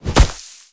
Sfx_creature_pinnacarid_hop_fast_01.ogg